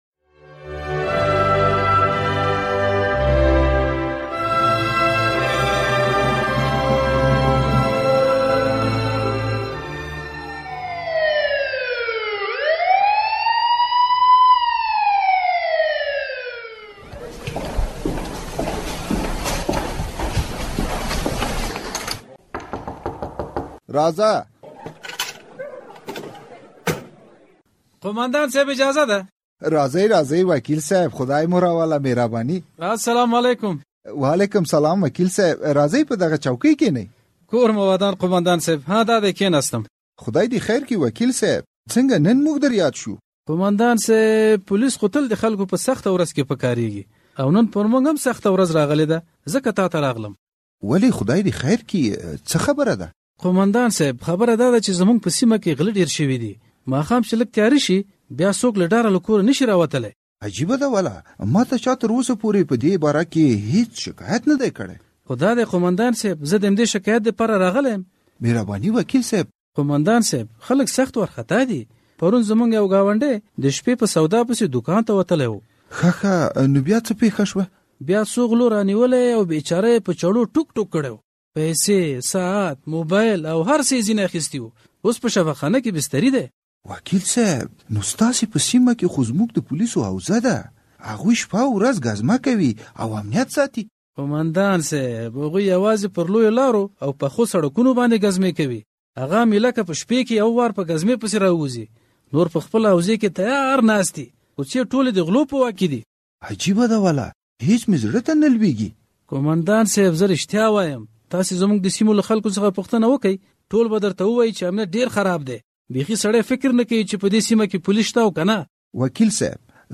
ډرامه